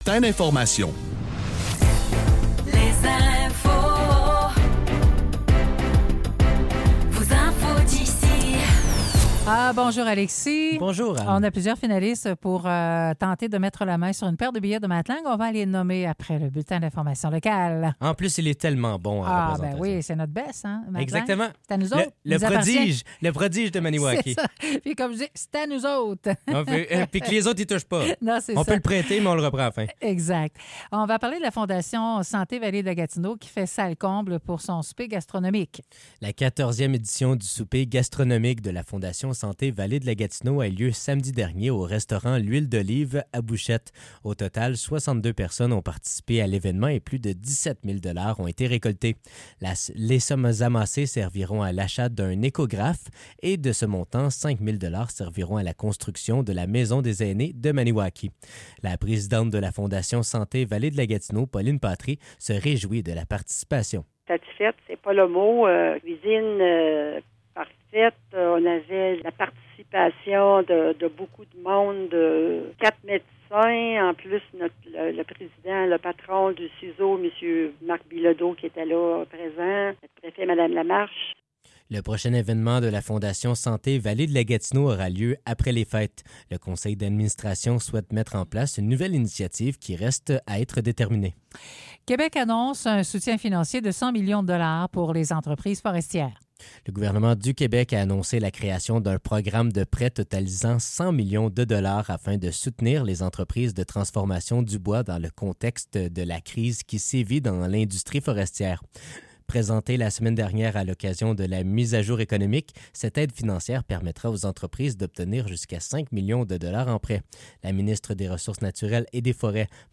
Nouvelles locales - 28 novembre 2024 - 10 h